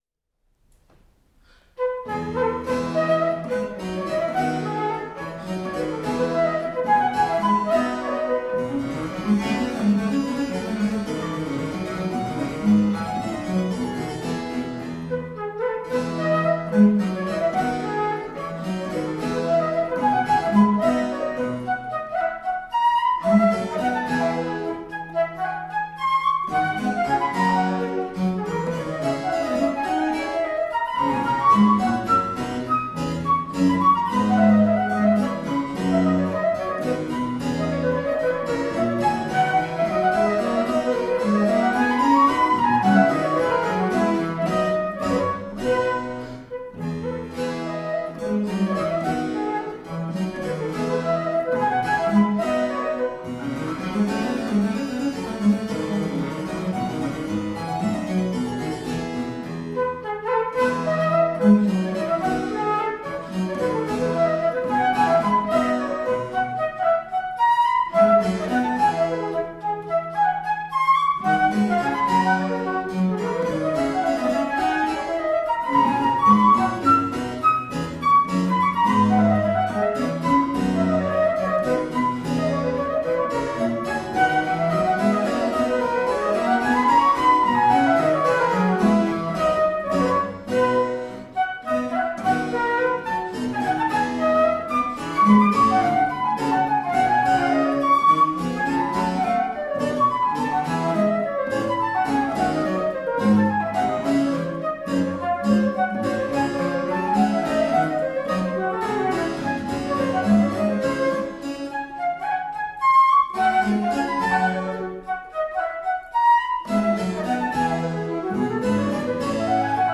Flautists with finesse, intelligence and grooves.
recorded at the Hakodate City Community Centre 2011.
Classical, Chamber Music, Baroque, Instrumental